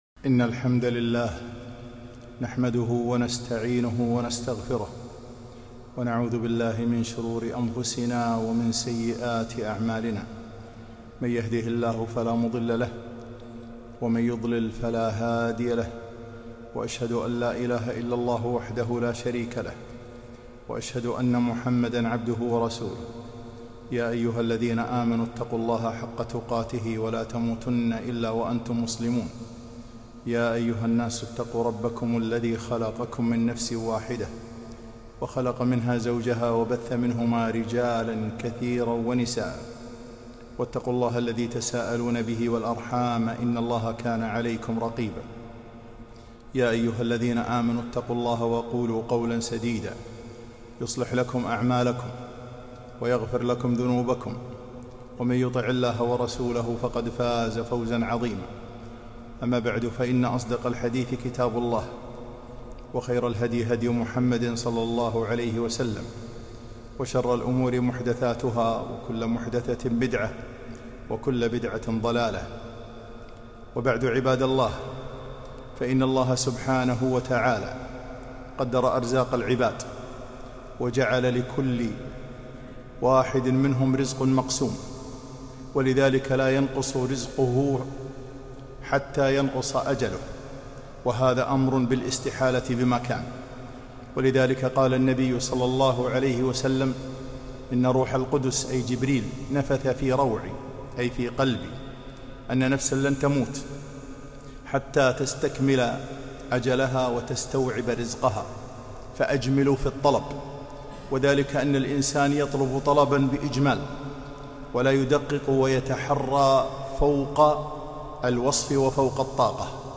خطبة - أسباب الرزق